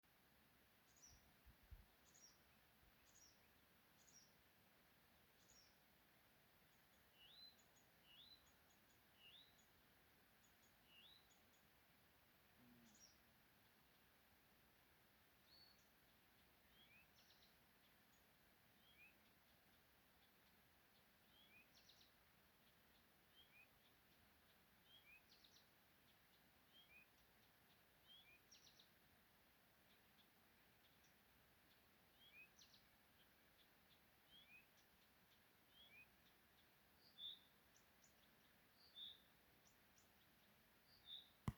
Putni -> Ķauķi ->
Krūmu ķauķis, Acrocephalus dumetorum
StatussDzied ligzdošanai piemērotā biotopā (D)